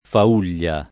[ fa 2 l’l’a ]